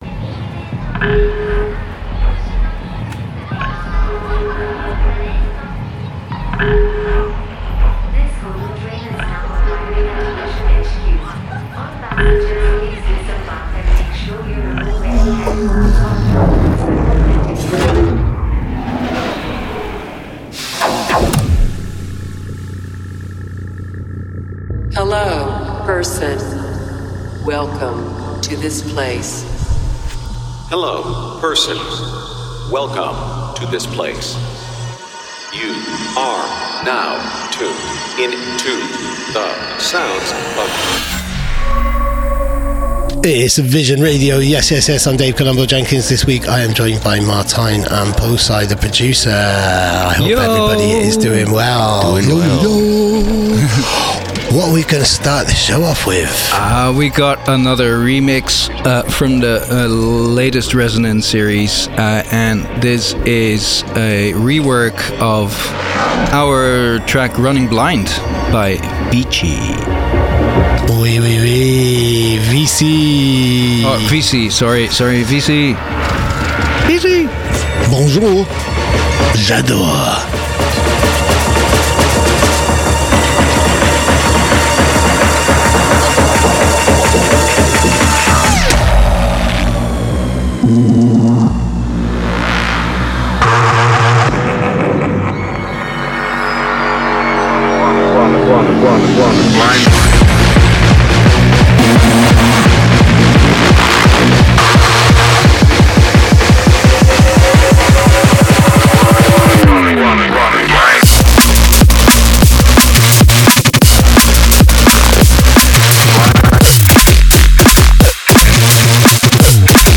features as the vocalist